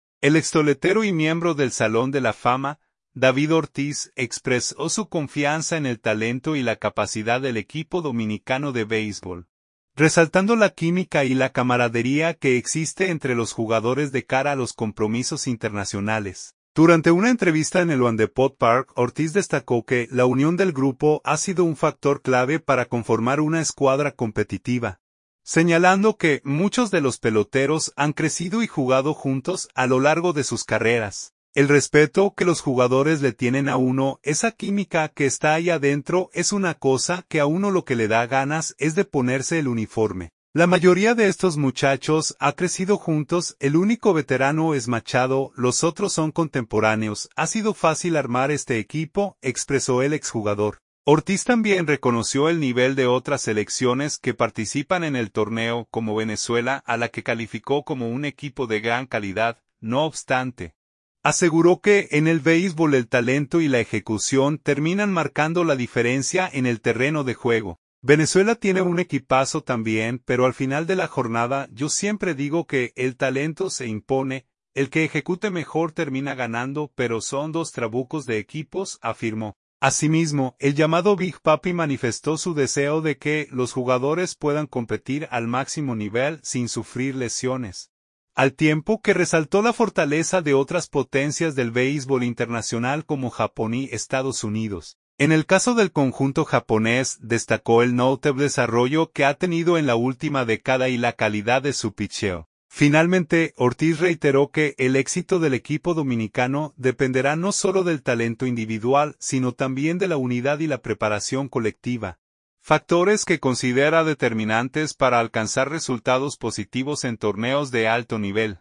Durante una entrevista en el LoanDepot Park, Ortiz destacó que la unión del grupo ha sido un factor clave para conformar una escuadra competitiva, señalando que muchos de los peloteros han crecido y jugado juntos a lo largo de sus carreras.